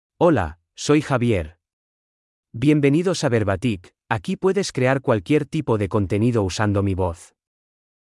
Javier — Male Spanish AI voice
Javier is a male AI voice for Spanish (Equatorial Guinea).
Voice sample
Listen to Javier's male Spanish voice.
Male
Javier delivers clear pronunciation with authentic Equatorial Guinea Spanish intonation, making your content sound professionally produced.